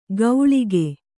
♪ gauḷige